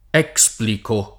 $Spliko], ecc.) modellata su quella delle basi latine (explico [lat.